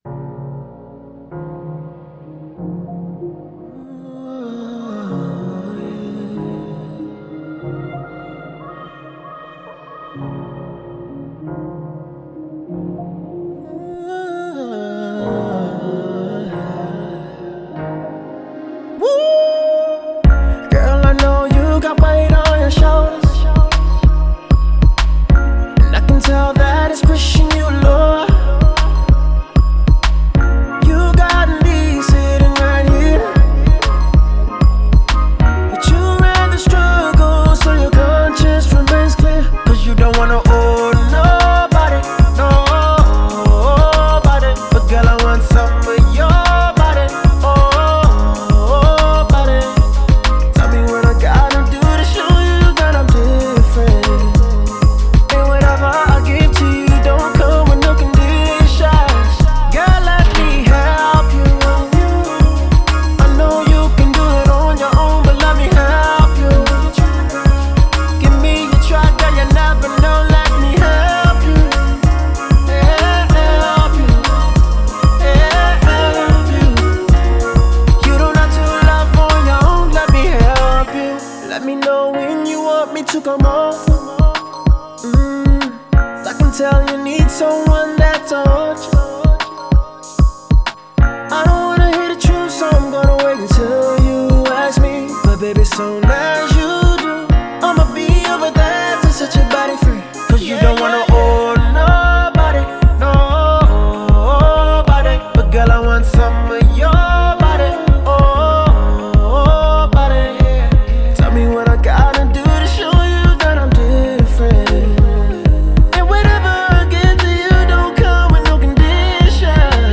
SINGLESR&B/SOUL